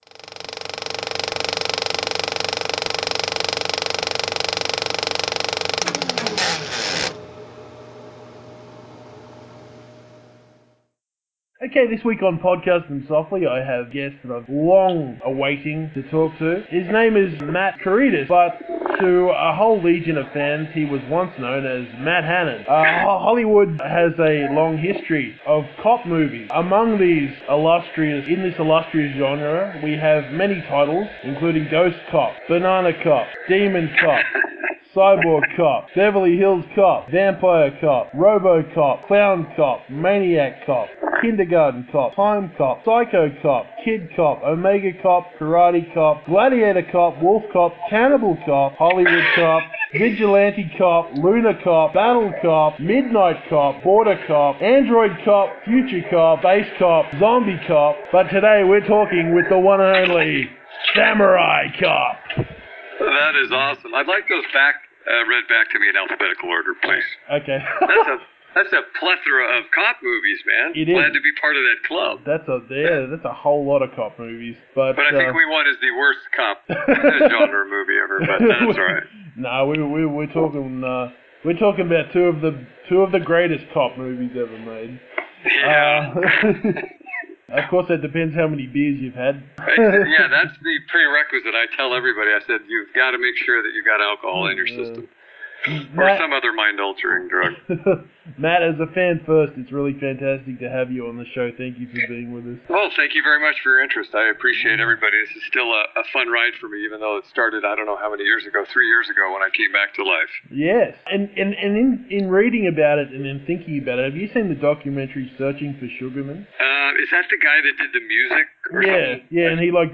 The Way of the Samurai Cop: An Interview